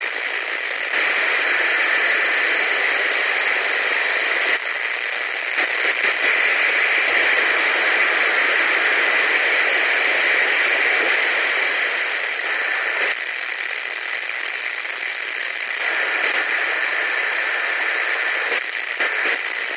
Рация звуки скачать, слушать онлайн ✔в хорошем качестве